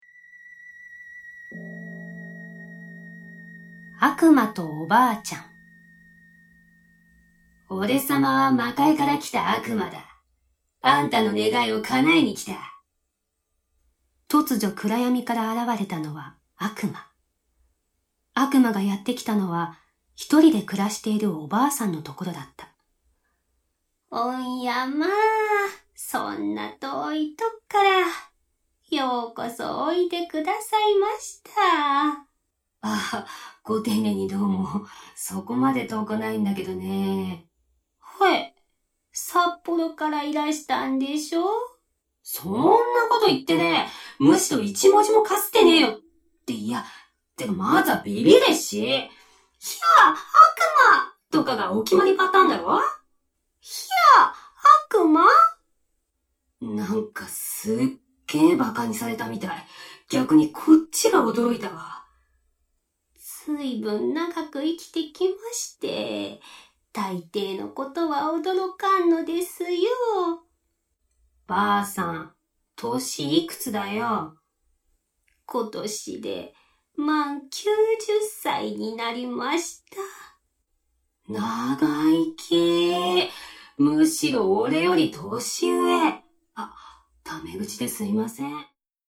SEにもこだわり、最先端技術を駆使し、擬似的に3D音響空間を再現、格別の臨場感を体感出来ます！